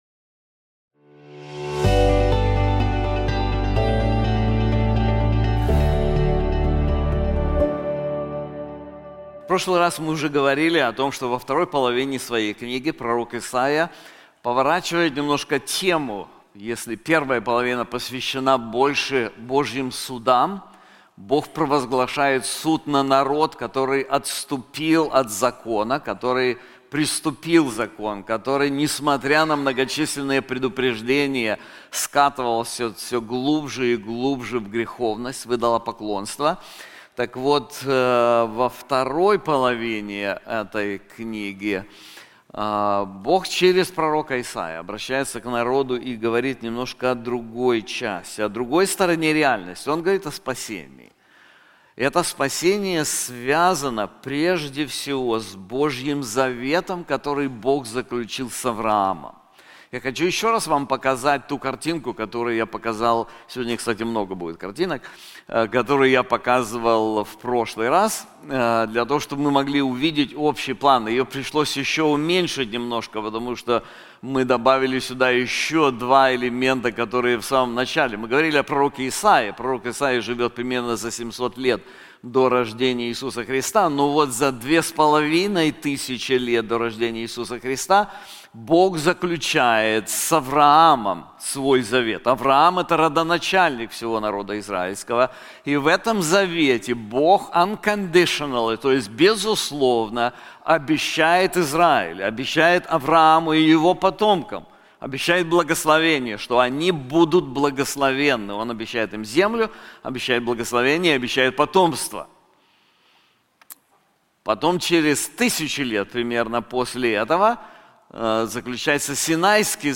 This sermon is also available in English:The God of Hope • Isaiah 40:12-31